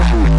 贝斯音乐样本包 " 贝斯15 - 声音 - 淘声网 - 免费音效素材资源|视频游戏配乐下载